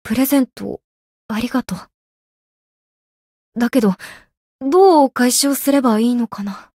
灵魂潮汐-南宫凛-春节（送礼语音）.ogg